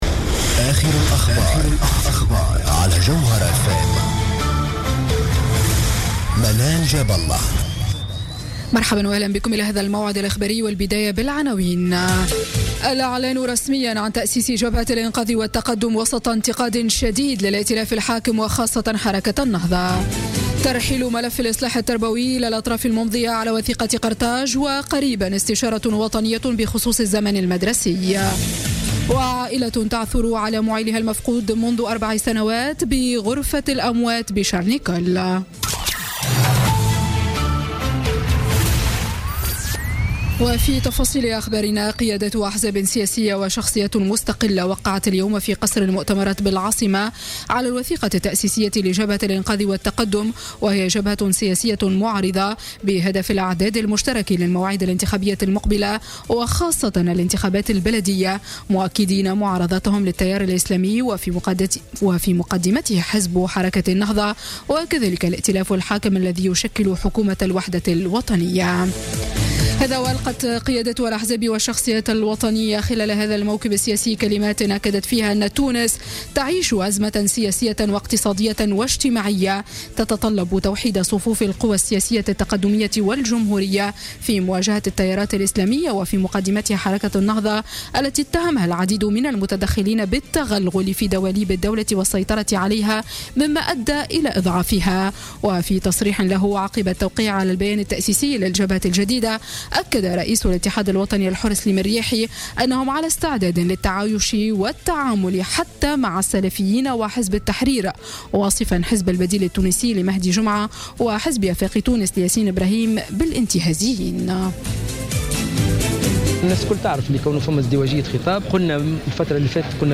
نشرة أخبار السابعة مساء ليوم الأحد 2 أفريل 2017